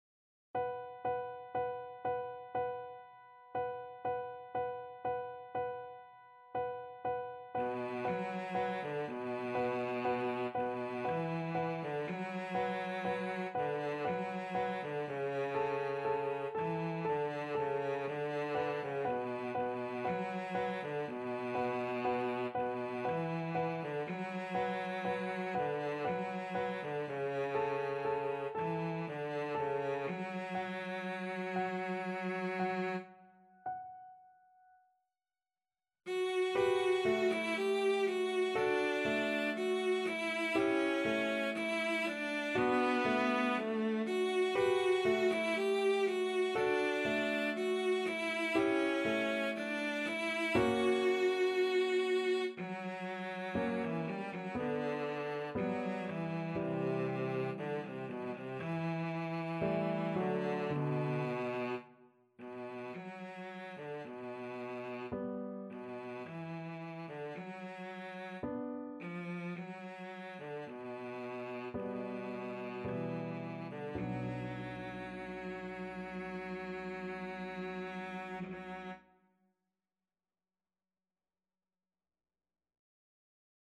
Free Sheet music for Cello
B minor (Sounding Pitch) (View more B minor Music for Cello )
Andante sostenuto =60
3/4 (View more 3/4 Music)
Classical (View more Classical Cello Music)